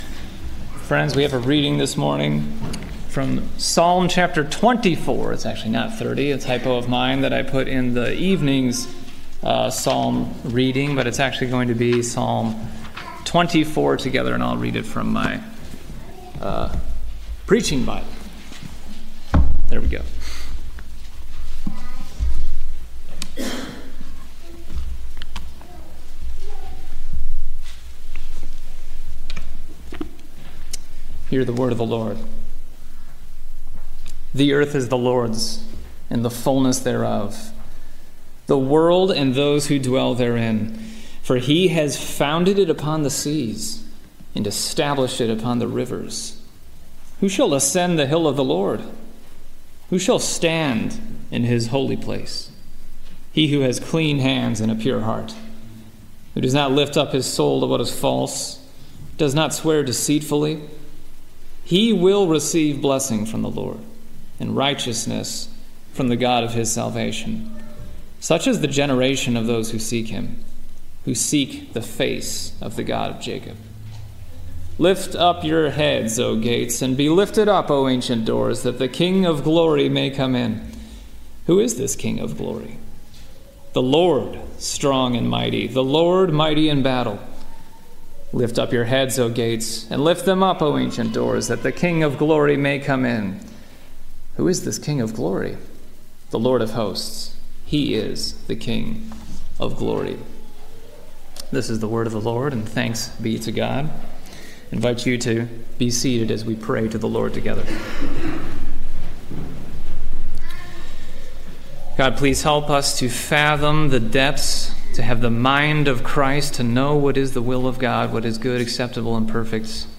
Morning Sermon